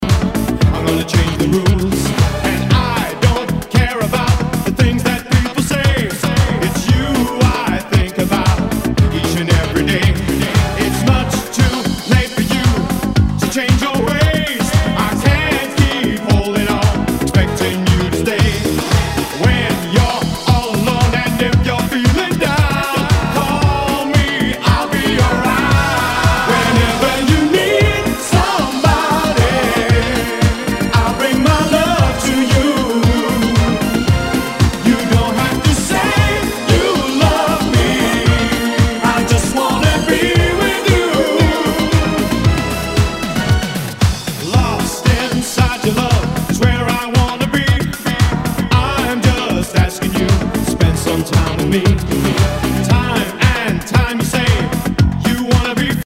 SOUL/FUNK/DISCO